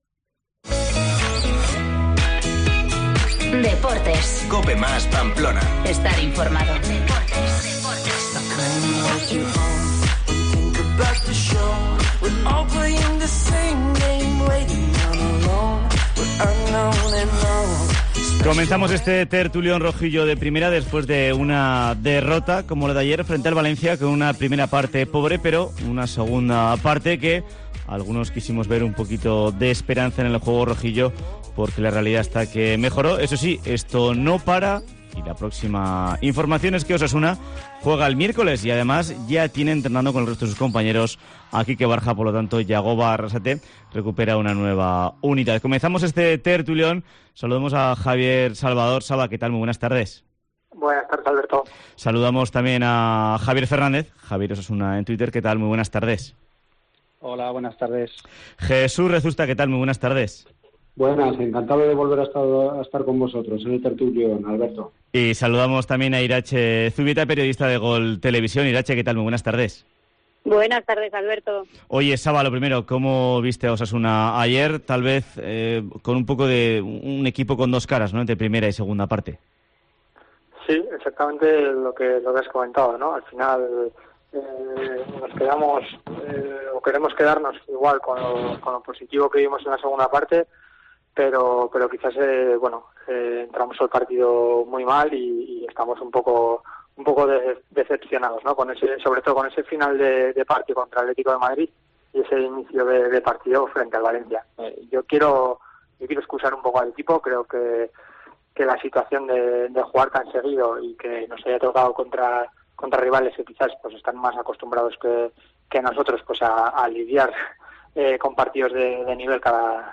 Tertulión rojillo de Primera tras el Valencia - Osasuna